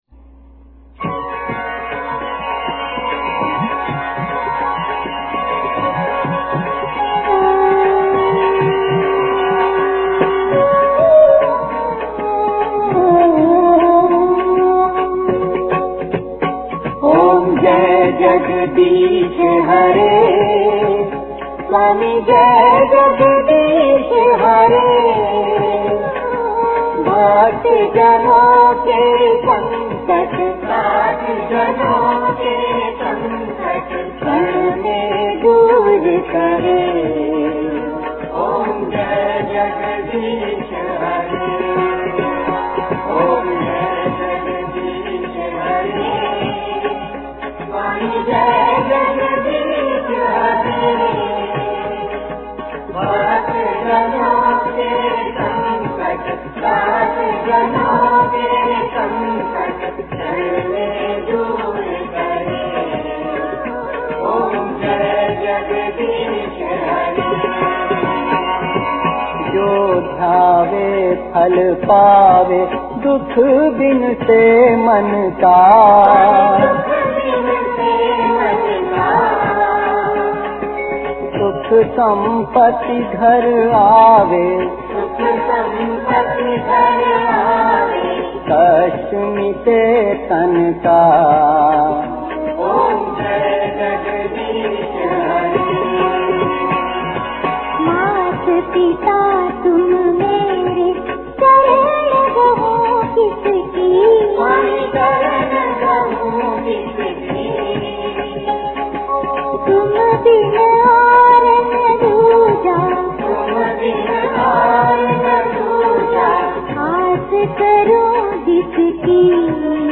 आरती - ॐ जय जगदीश हरे